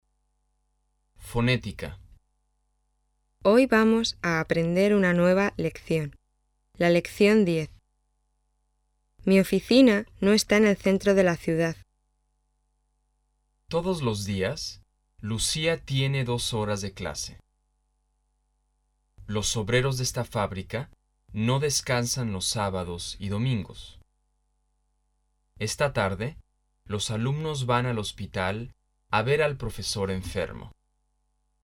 在句子中，有些词重读，有些词不重读。
较长的句子不可能一口气读完。因此必须在句子内部做适当的停顿。处在两次停顿之间的单词构成语调群。